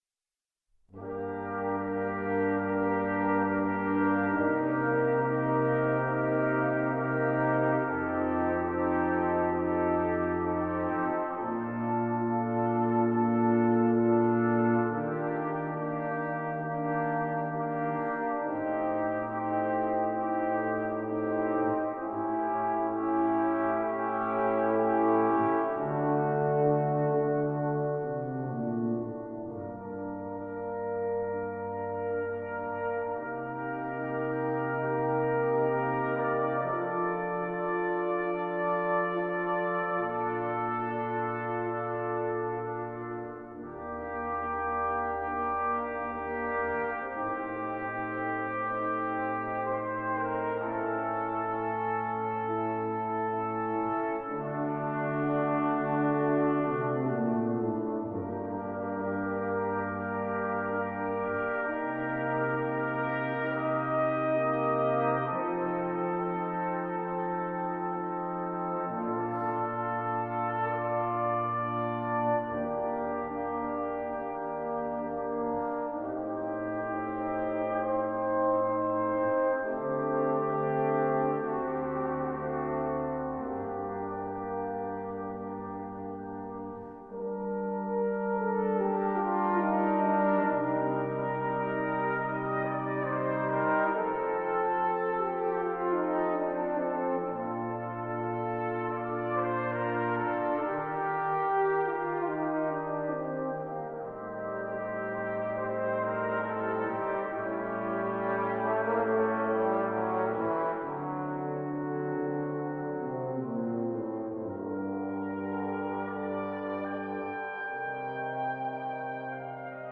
Trumpets in C.
(live performance).